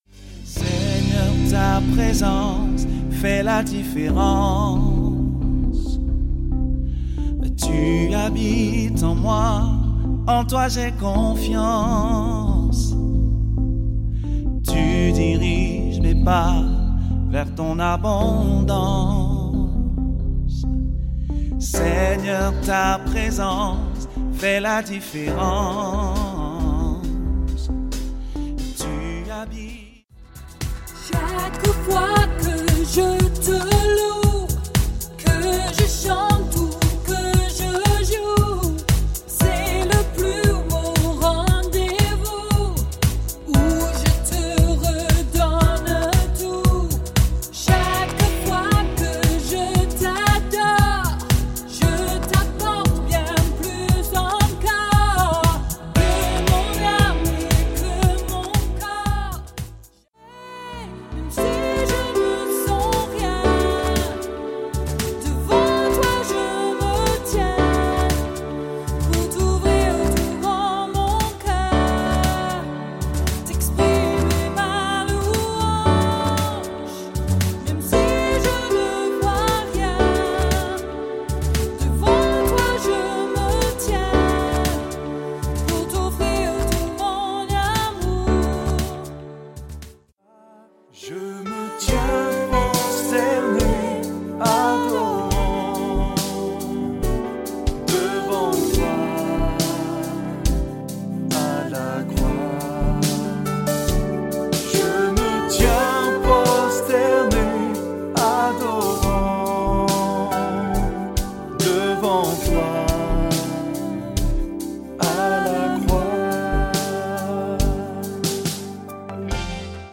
Genre : Louange francophone, Pop/rock
C’est frais, actuel avec un son incroyable.
Enregistré en studio